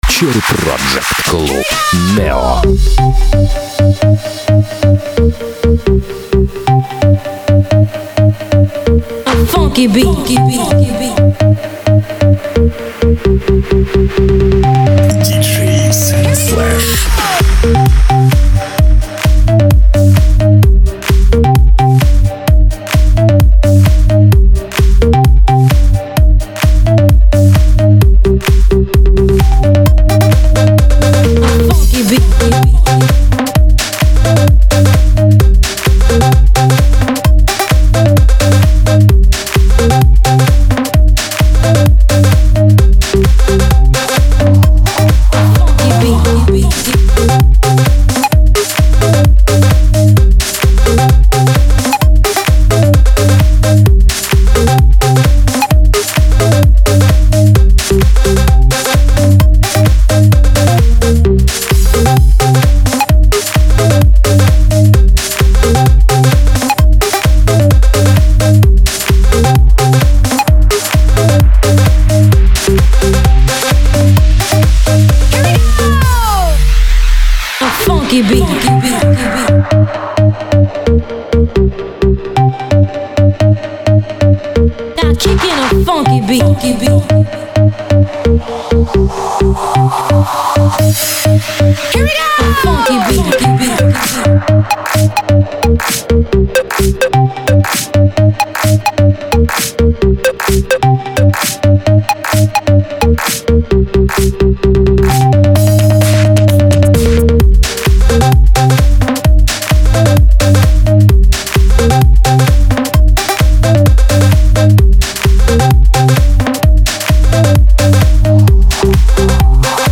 Жанр: Electro